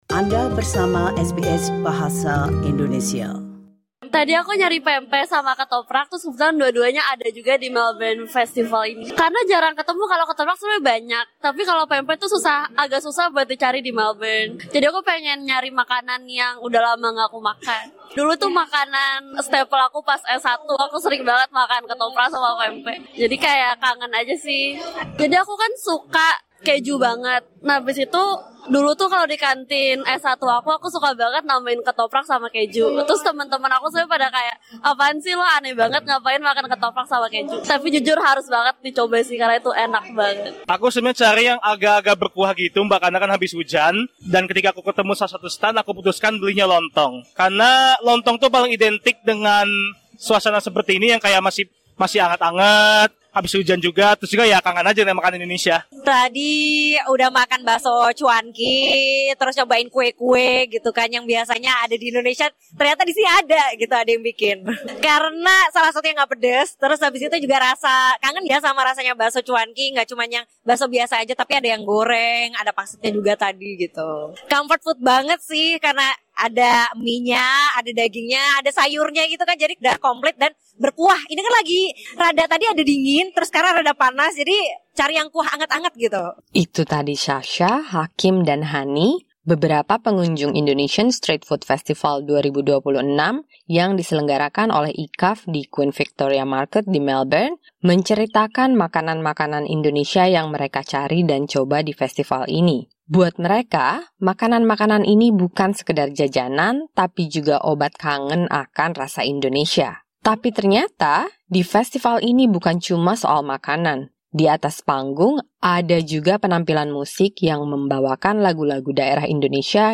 Some attendees also shared what their favourites are from the event.